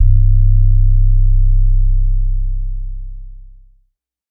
SOUTHSIDE_808_thick_C.wav